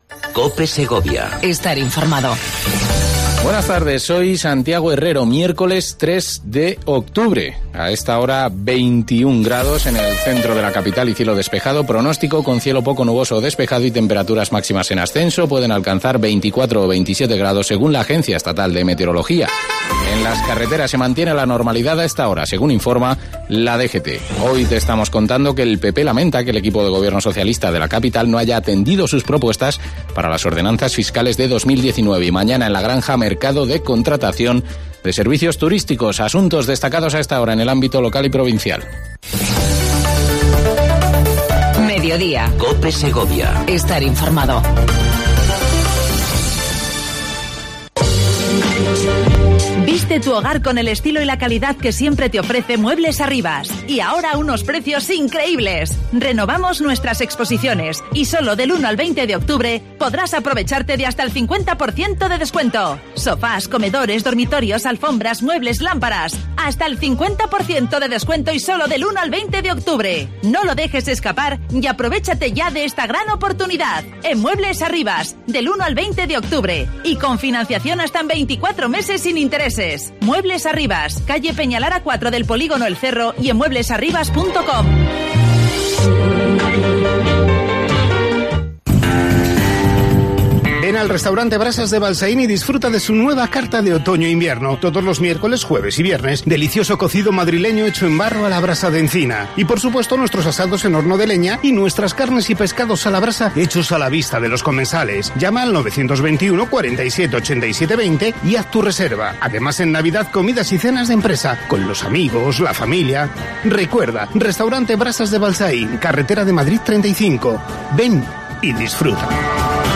AUDIO: Entrevista a Ángel Galindo, portavoz del grupo municipal de Izquierda Unida